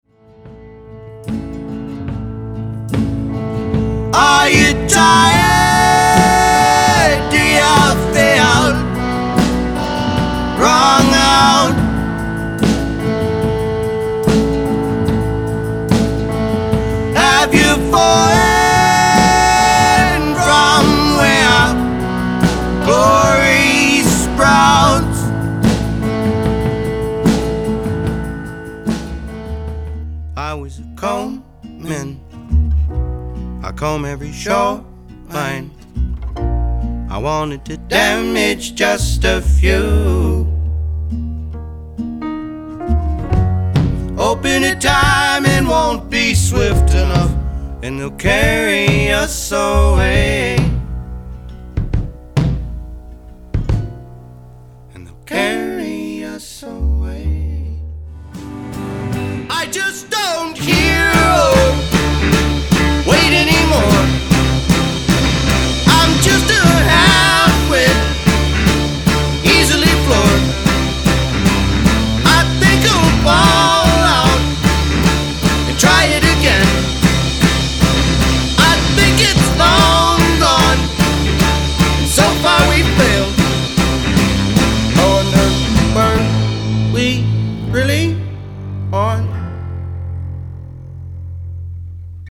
Genre/Style: Rock, folk, modern, alternative